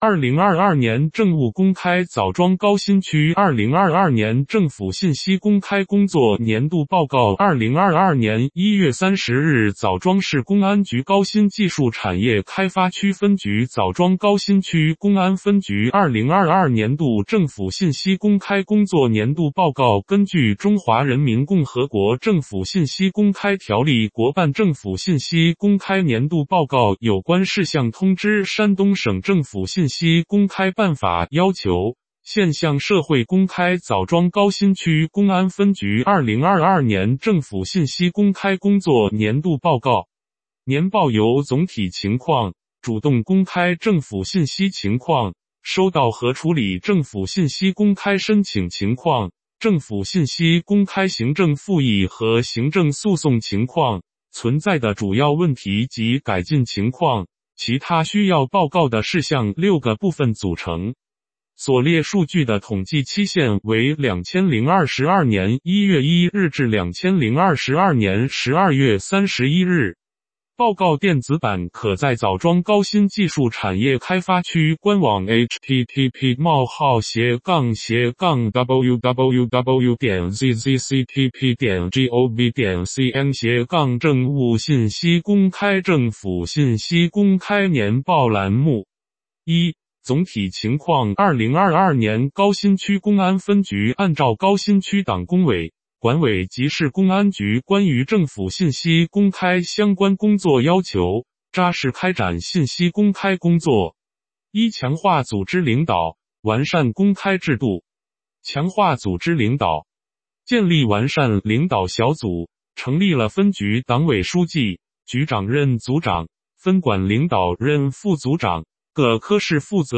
点击接收年报语音朗读 枣庄高新区公安分局2022年度政府信息公开工作年度报告 作者： 来自： 时间：2023-01-31 ‍ 根据《中华人民共和国政府信息公开条例》《国办政府信息公开年度报告有关事项通知》《山东省政府信息公开办法》要求，现向社会公开枣庄高新区公安分局2022年政府信息公开工作年度报告。